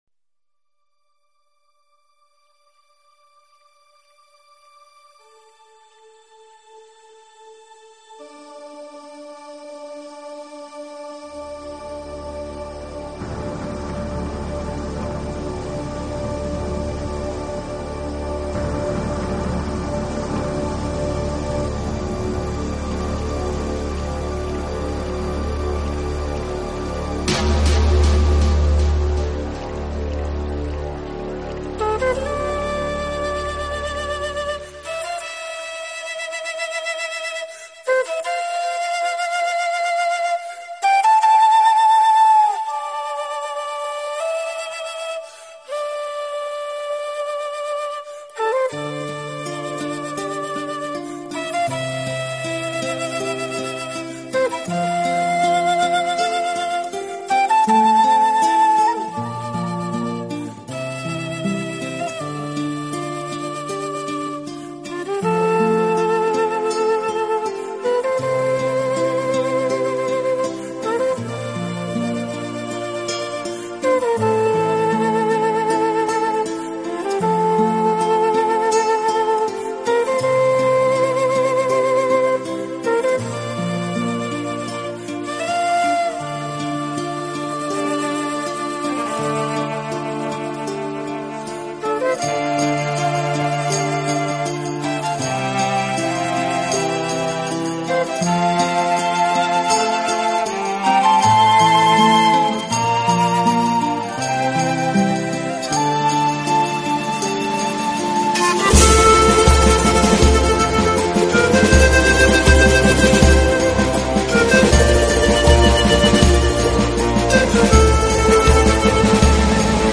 音乐流派：Native American